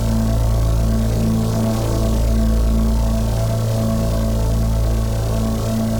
Index of /musicradar/dystopian-drone-samples/Non Tempo Loops
DD_LoopDrone1-A.wav